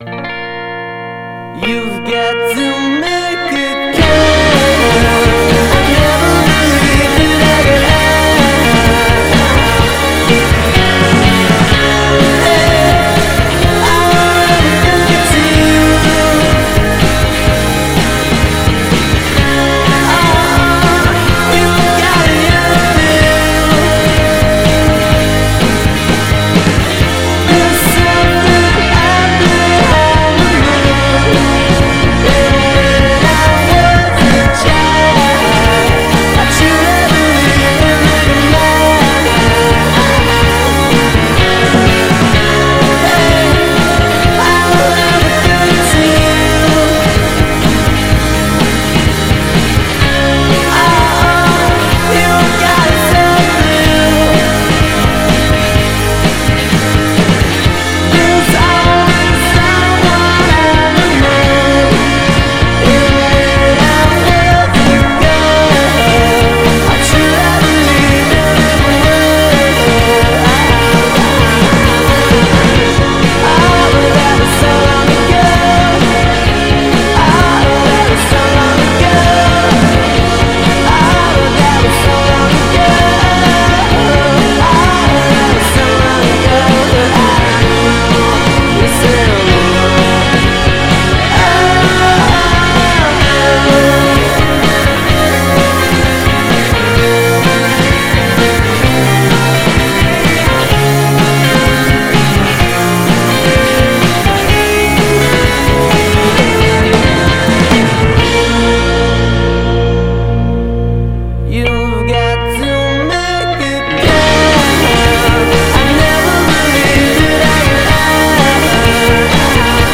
Indie Indie rock Indie pop